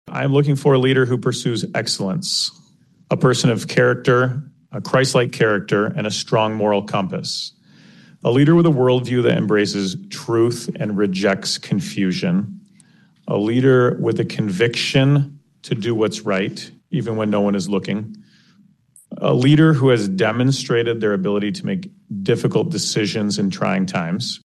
Former board chairman and District 5 Commissioner Joe Moss of Hudsonville spoke for most of his colleagues in attributes he would like to see in the new operational leader.